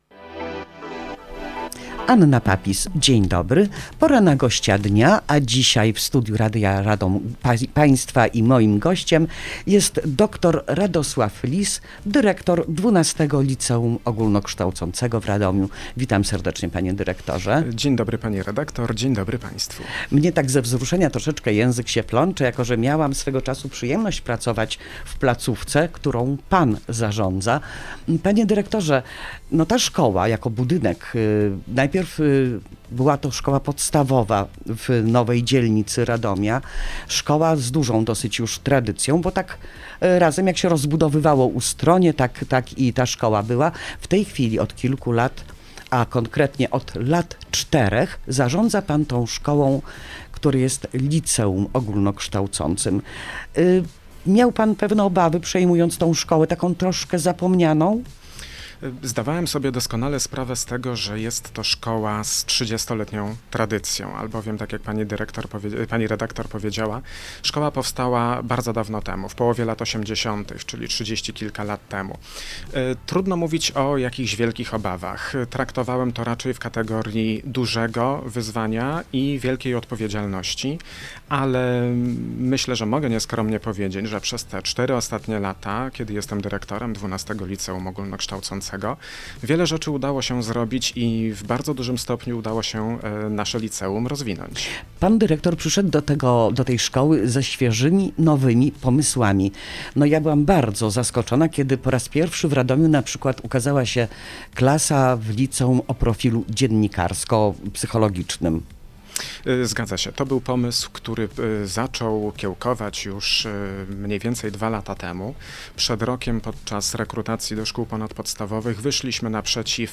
w studiu Radia Radom